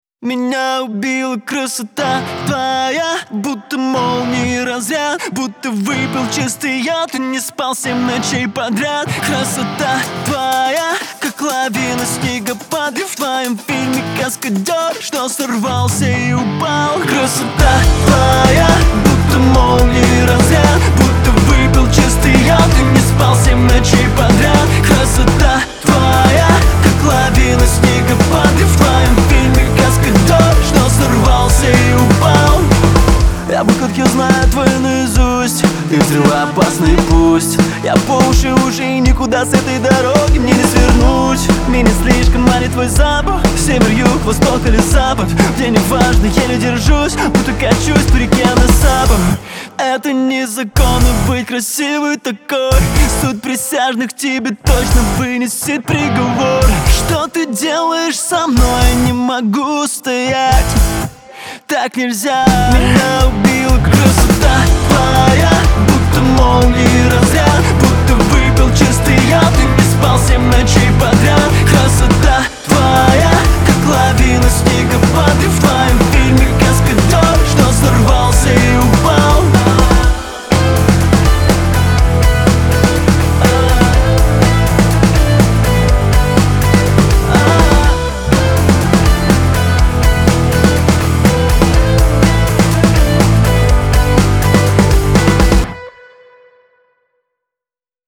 pop
эстрада , диско , танцевальная музыка